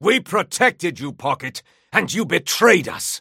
Mo & Krill voice line - We protected you Pocket, and you betrayed us!